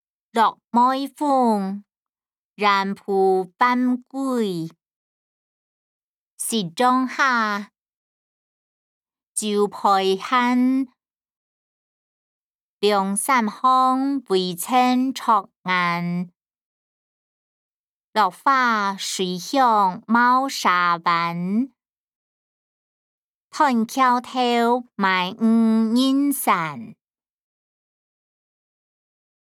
詞、曲-落梅風．遠浦帆歸音檔(海陸腔)